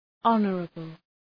Προφορά
{‘ɒnərəbəl}